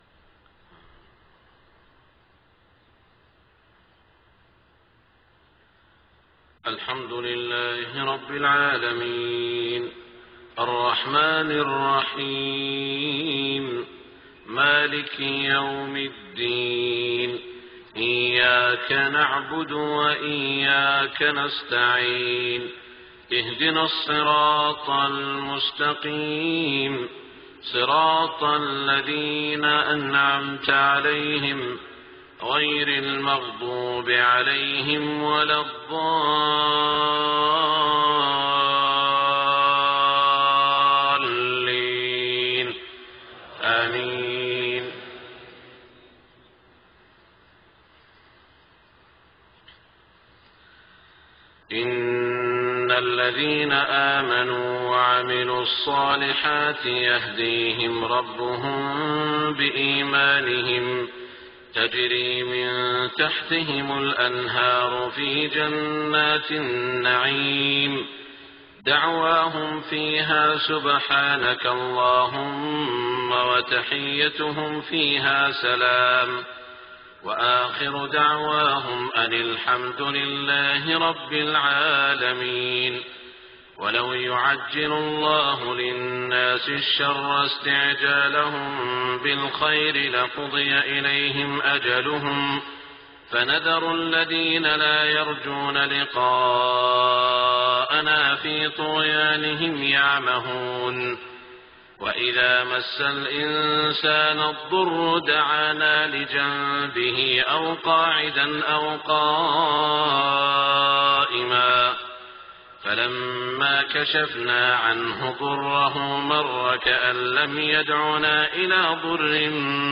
صلاة الفجر 4-7-1426 من سورة يونس > 1426 🕋 > الفروض - تلاوات الحرمين